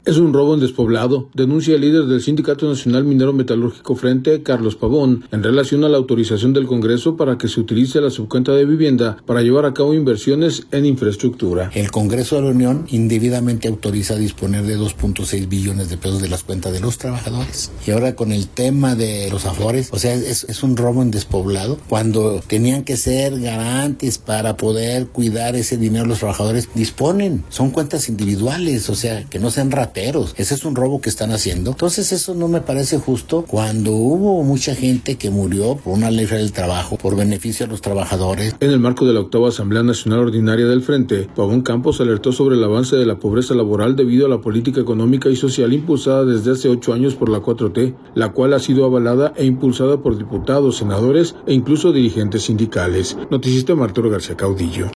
En el marco de la octava Asamblea Nacional Ordinaria del Frente